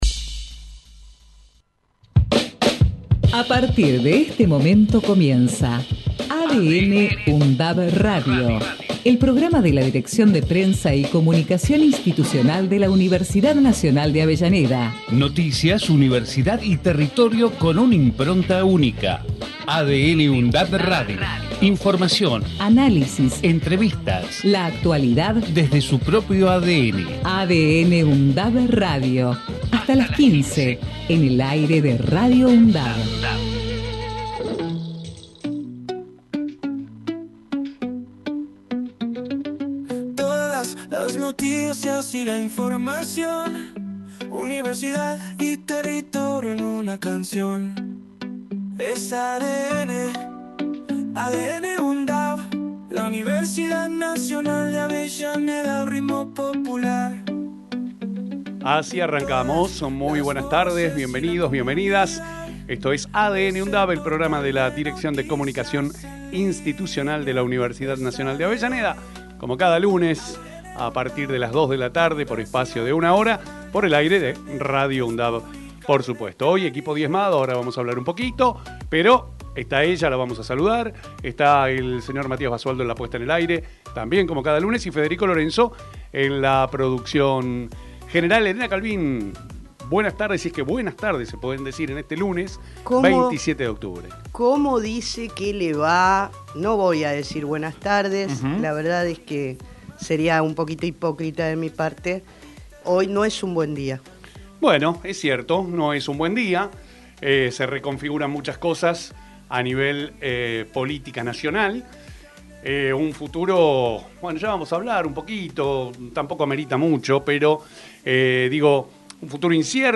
Texto de la nota: El programa de la Dirección de Prensa y Comunicación Institucional de la Universidad Nacional de Avellaneda en su emisora Radio UNDAV, busca transmitir la impronta de la Universidad, su identidad, su ADN de una forma actual y descontracturada, con rigurosidad y calidad informativa. Noticias, universidad y territorio son los tres ejes que amalgaman la nueva propuesta a través de la imbricación y la interrelación de las temáticas que ocupan y preocupan a la comunidad local, zonal y nacional desde una mirada universitaria, crítica y constructiva a través de voces destacadas del mundo académico, político, cultural y social.
ADN|UNDAV – Radio tiene la vocación de ser un aporte en tal sentido, a través de secciones como “Temas de la Uni”, “Entrevistas”, “Lo que pasa” y “En comunidad”.